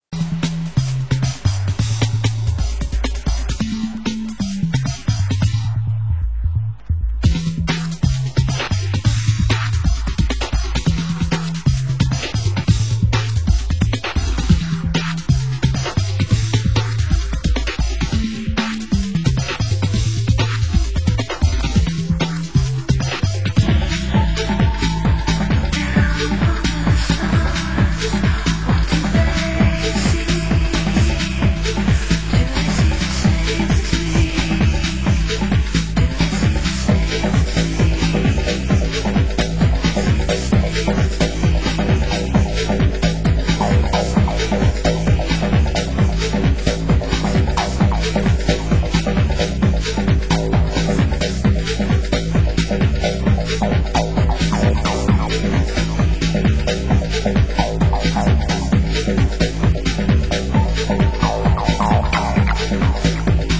Format: Vinyl 12 Inch
Genre: Progressive